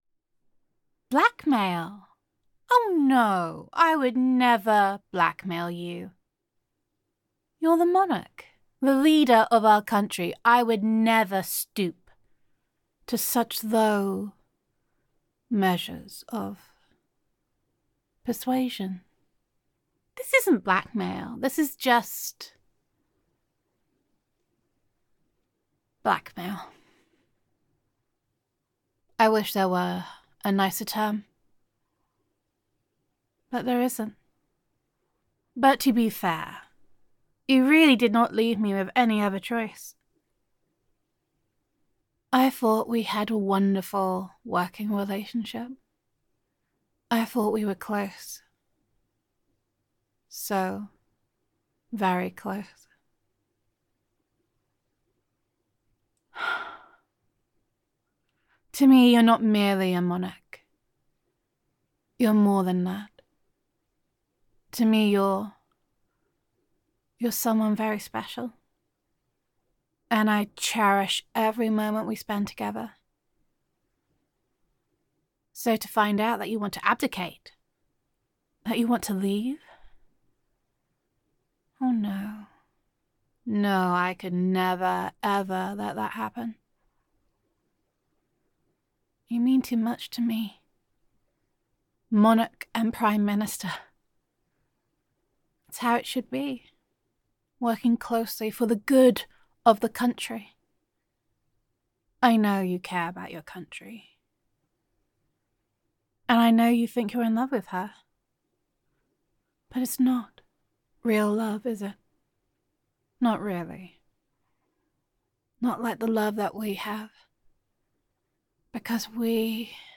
[F4A] Blackmail Is Such an Unpleasant Word [Corgi Napping][Monarch Listener][Prime Minister Roleplay][Creepy][Blackmail][Only a Demon Would Kidnap a Corgi][Gender Neutral][The Prime Minister Really Does Not Want You to Adjudicate]